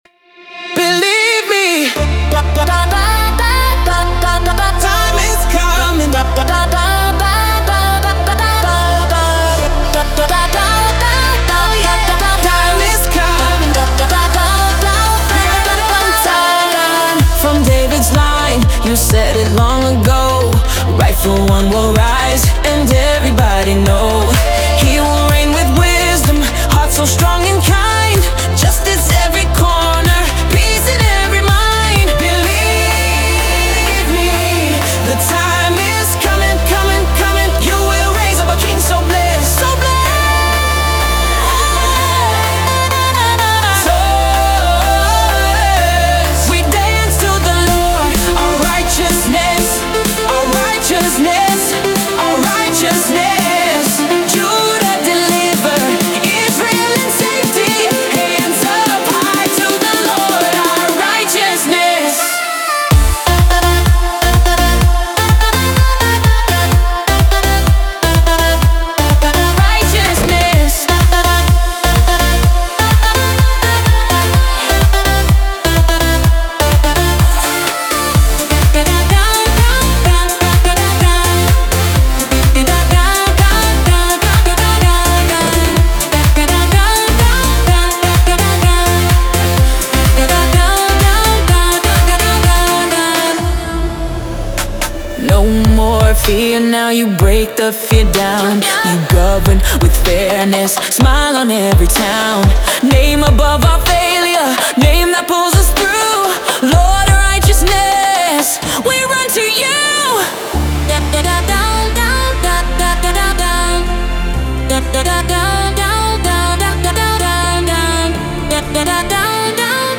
De toon is gelovig, hoopvol en feestelijk.
The tone is faithful, hopeful, and celebratory.